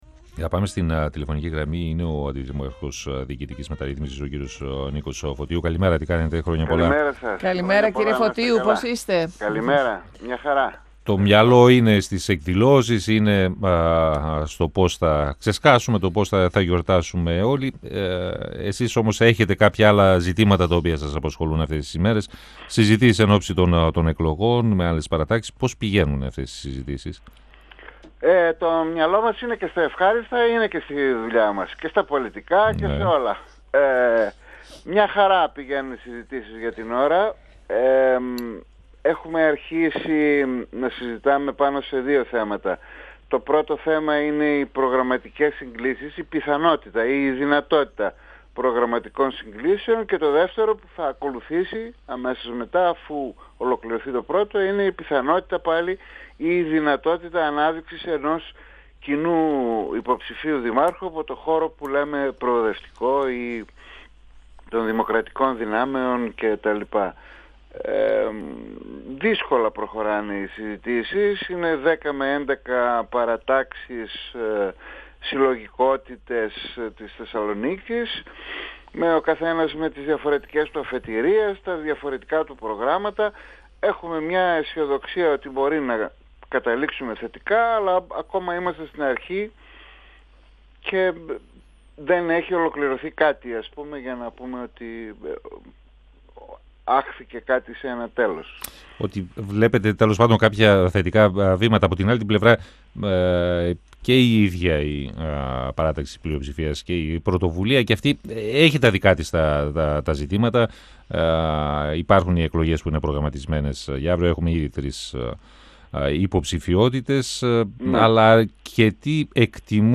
Σε εξέλιξη είναι οι συζητήσεις μεταξύ εκπροσώπων της «Πρωτοβουλίας» κι άλλων δημοτικών παρατάξεων ενόψει των εκλογών που θα διεξαχθούν τον επόμενο χρόνο. Αντικείμενο των συζητήσεων είναι να βρεθούν προγραμματικές συγκλίσεις, ανέφερε ο αντιδήμαρχος Διοικητικής Μεταρρύθμισης, Νίκος Φωτίου, μιλώντας στον 102FM της ΕΡΤ3 και αναγνώρισε ότι υπάρχουν αρκετά εμπόδια. Σχετικά με τεκταινόμενα στην «Πρωτοβουλία», ο κ. Φωτίου εκτίμησε ότι οι διεργασίες πραγματοποιούνται στο σωστό χρονικό διάστημα, ενώ δεν διέκρινε σημαντικούς κινδύνους για την πορεία της παράταξης.
Συνεντεύξεις